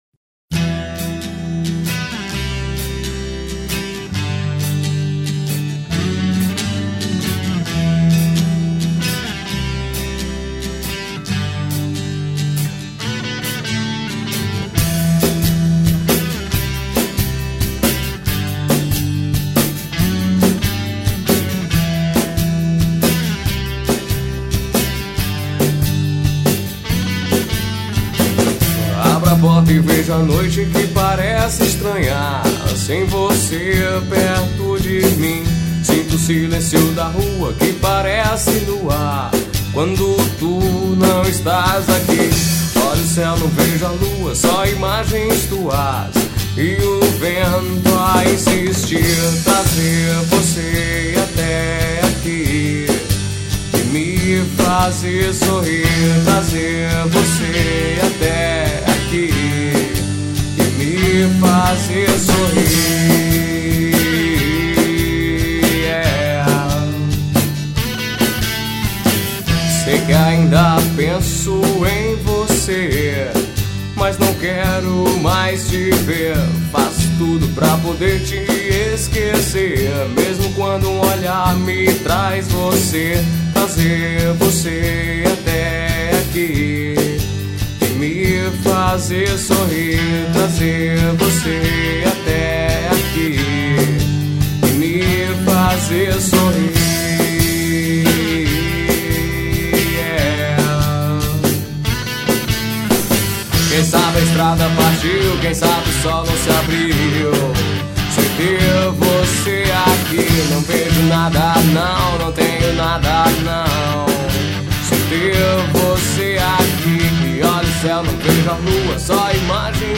2504   03:45:00   Faixa: 2    Rock Nacional